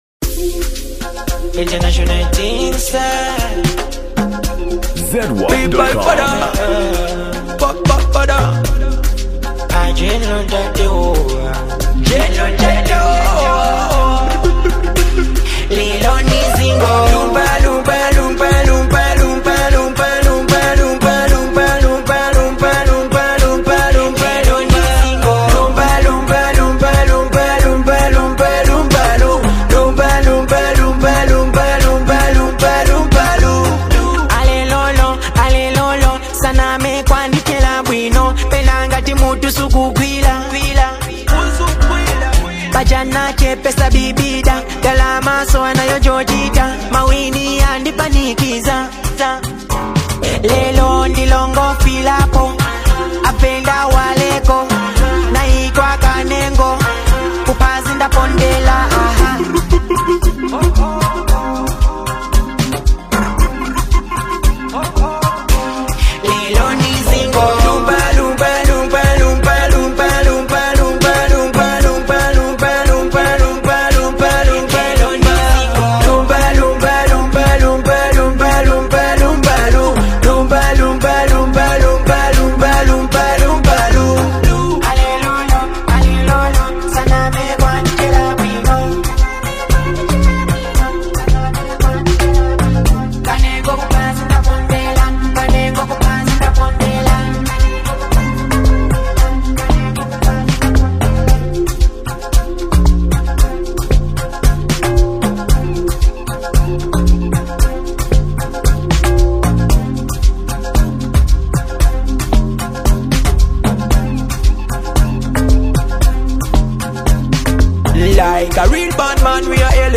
Genre: Afro-Beats